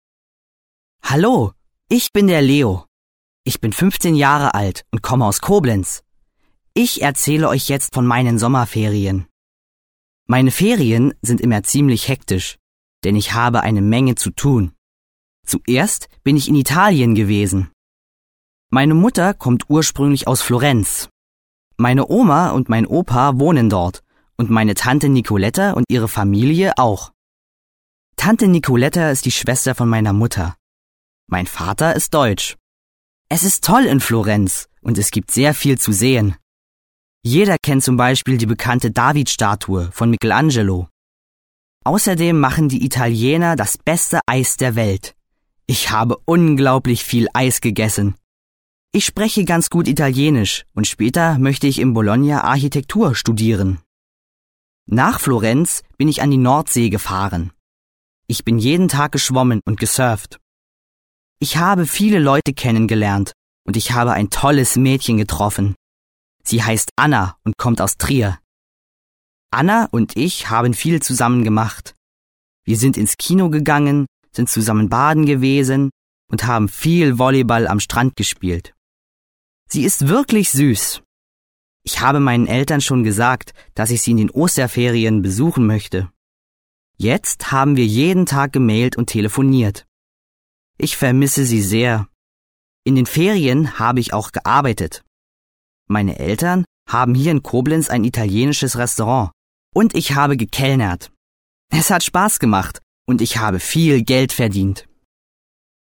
Lytteprøve